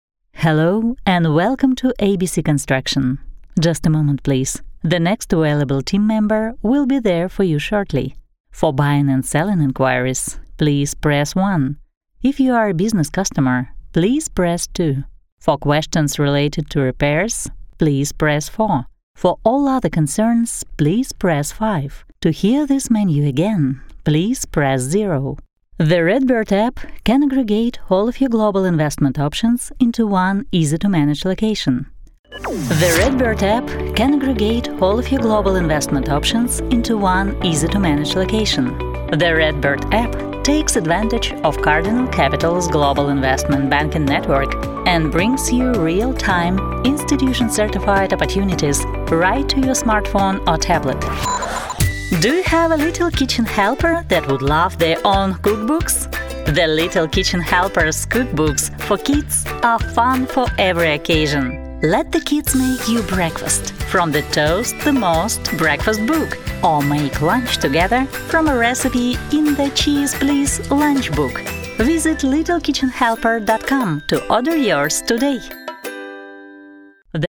Тракт: Тракт записи голоса на сегодня такой: Микрофон Neumann TLM 103 PreSonus Revelator Запись и редактирование MacBook Pro Профессиональная акустическая кабина WhisperRoom
Демо-запись №2 Скачать